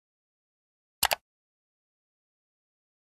جلوه های صوتی
دانلود صدای موس کامپیوتر 1 از ساعد نیوز با لینک مستقیم و کیفیت بالا